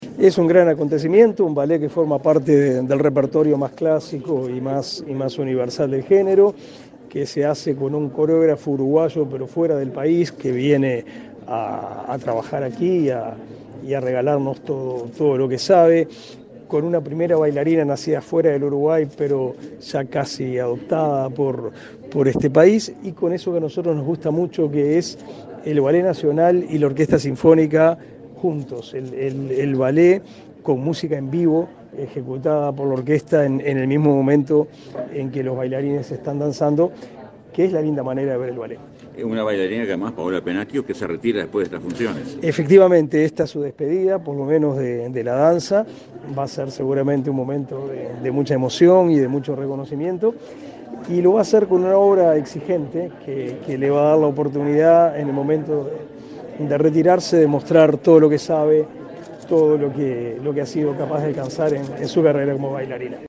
Declaraciones a la prensa del ministro de Educación y Cultura, Pablo da Silveira
Declaraciones a la prensa del ministro de Educación y Cultura, Pablo da Silveira 28/07/2022 Compartir Facebook X Copiar enlace WhatsApp LinkedIn El Ballet Nacional del Sodre presentó, este 28 de julio, la versión completa del ballet Raymonda, producción artística que se estrenará en agosto bajo al dirección de María Noel Riccetto. Tras el evento, el ministro Da Silveira efectuó declaraciones a la prensa.